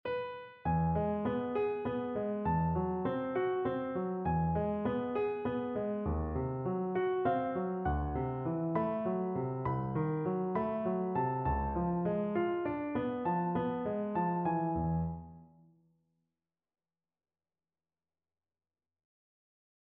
This project included the task for me to write three different ornamented versions of the following melody written for the piano: